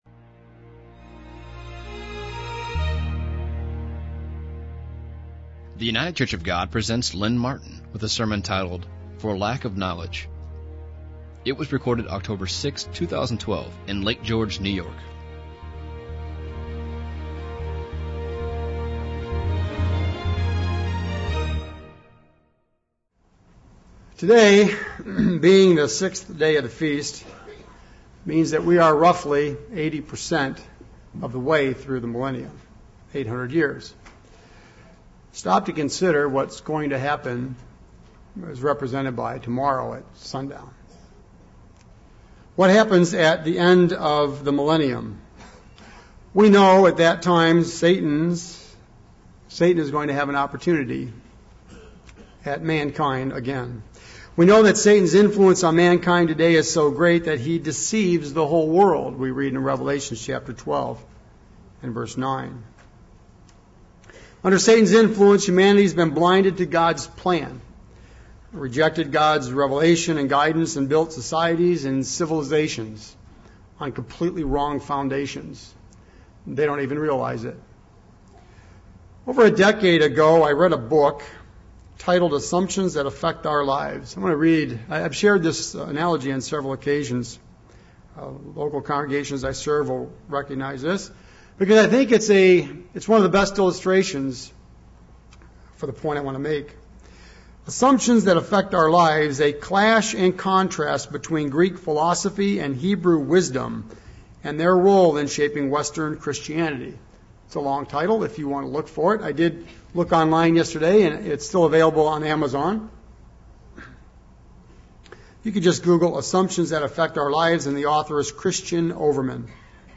This sermon was given at the Lake George, New York 2012 Feast site.